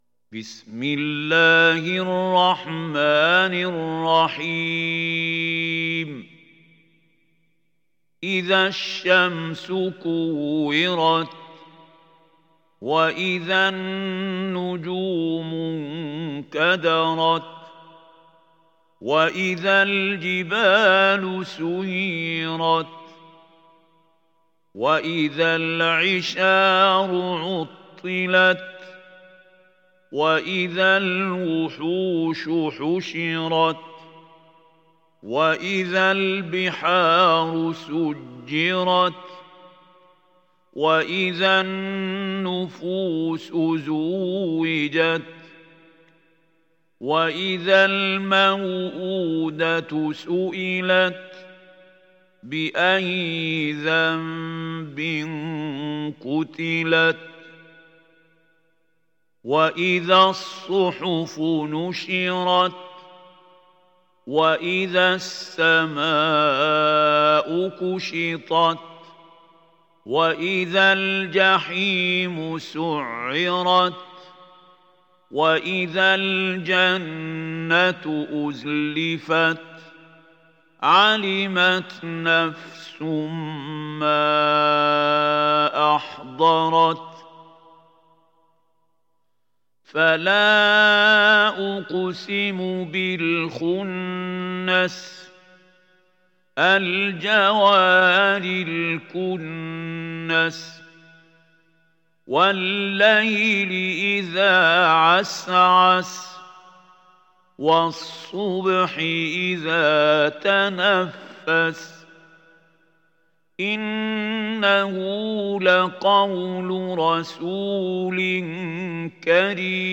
تحميل سورة التكوير mp3 بصوت محمود خليل الحصري برواية حفص عن عاصم, تحميل استماع القرآن الكريم على الجوال mp3 كاملا بروابط مباشرة وسريعة